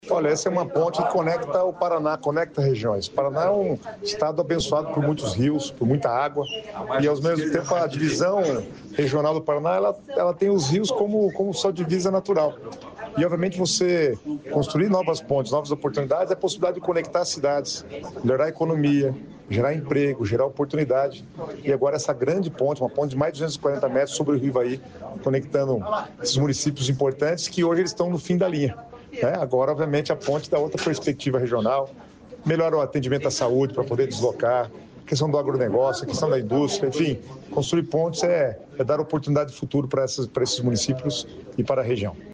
Sonora do secretário das Cidades, Guto Silva, sobre nova ponte no Rio Ivaí
SONORA GUTO SILVA - NOVA PONTE.mp3